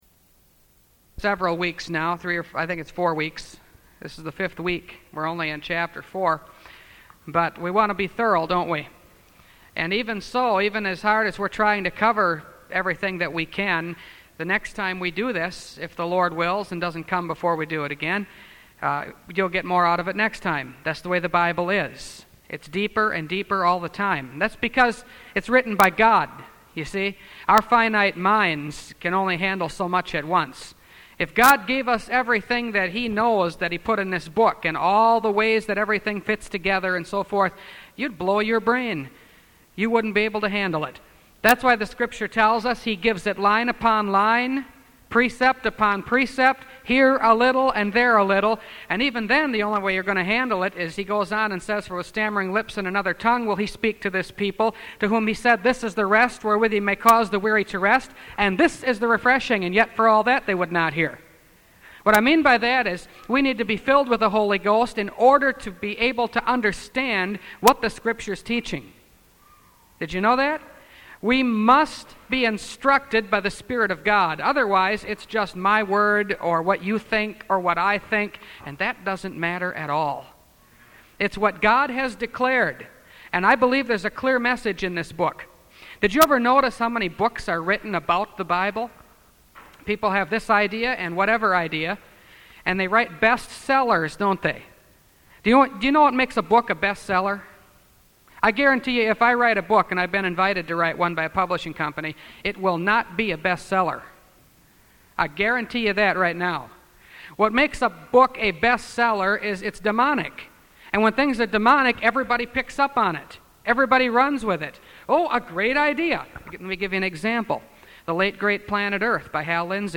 Revelation Series – Part 5 – Last Trumpet Ministries – Truth Tabernacle – Sermon Library